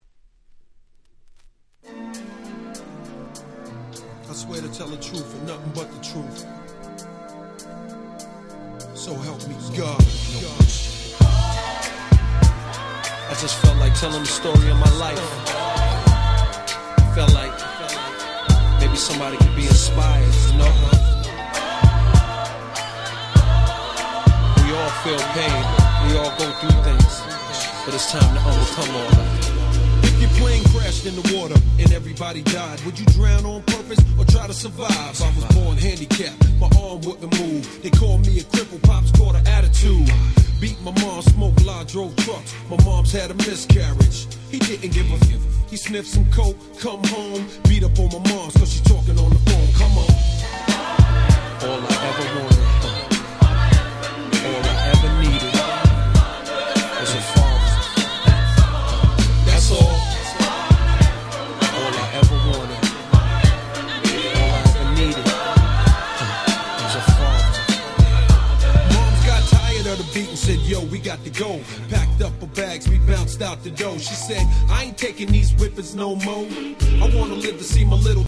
98' Big Hit Hip Hop !!
Club向けの楽曲か？と聞かれれば答えはNoですが非常に温かみのある壮大な名曲です！！
90's Boom Bap ブーンバップ